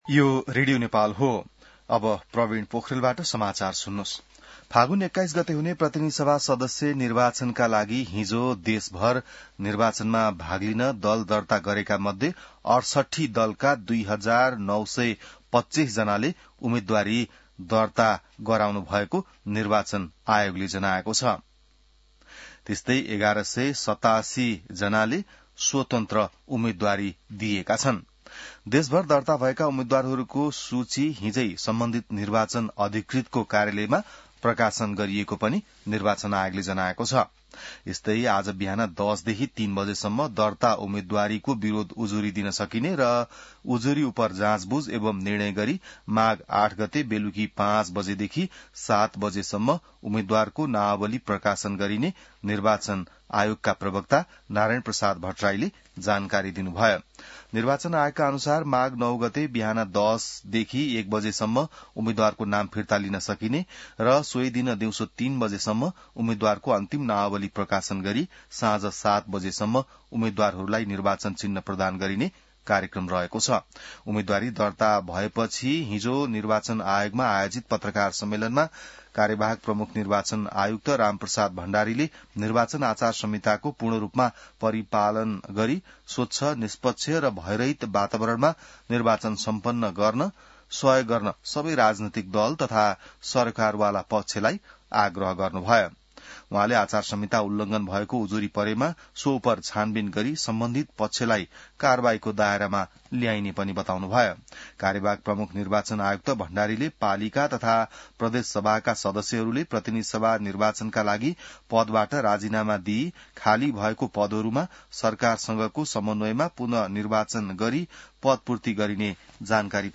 बिहान ६ बजेको नेपाली समाचार : ७ माघ , २०८२